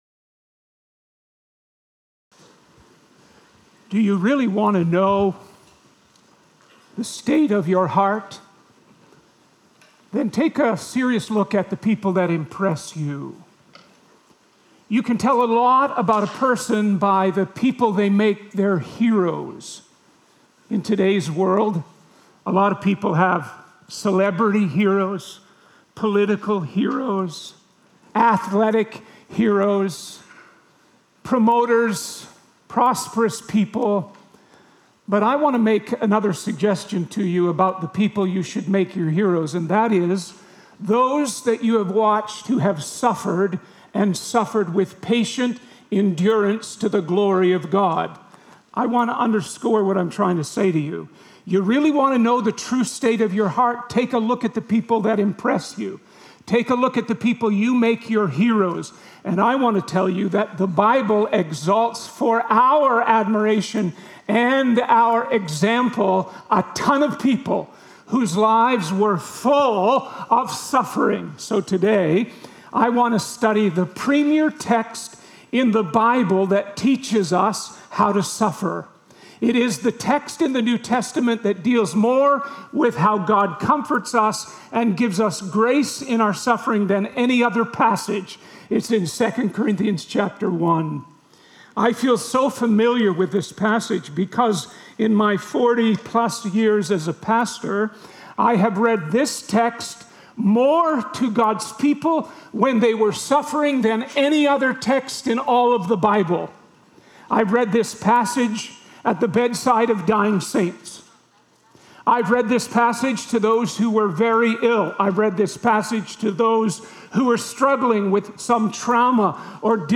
City Centre Church - Mississauga